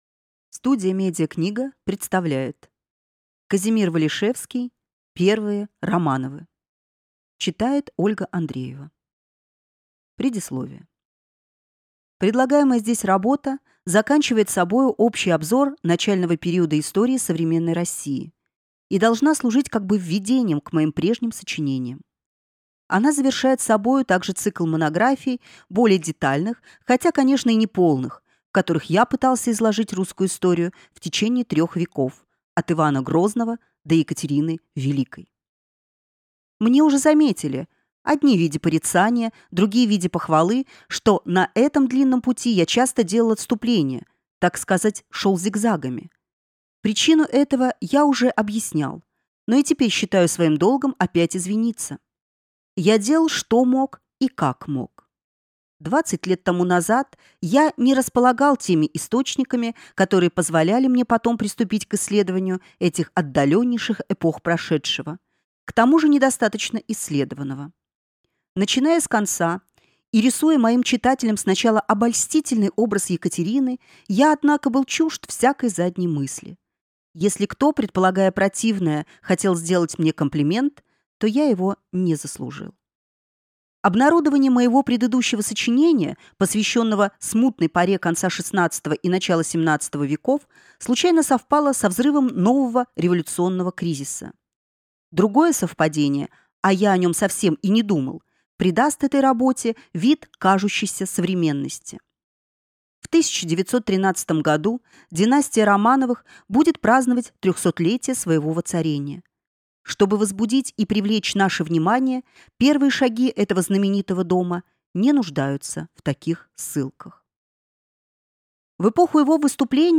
Аудиокнига Первые Романовы | Библиотека аудиокниг